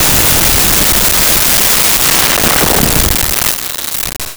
Rifle 1
Rifle_1.wav